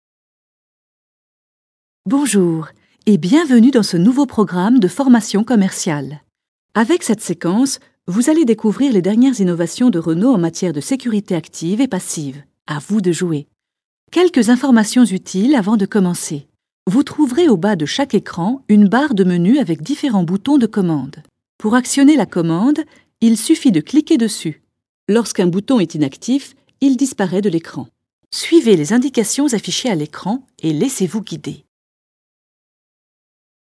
VOIX-OFF AUDIOGUIDES, E-LEARNING, DOCUMENTAIRES, INSTUTIONNELS
Sprechprobe: eLearning (Muttersprache):